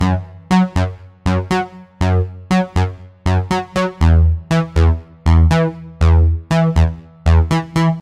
loops basses dance 120 - 2
Téléchargez et écoutez tous les sons et loops de basses style dance music tempo 120bpm enregistrés et disponibles sur les banques de sons gratuites en ligne d'Universal-Soundbank pour tous les musiciens, cinéastes, studios d'enregistrements, DJs, réalisateurs, soundesigners et tous ceux recherchant des sons de qualité professionnelle.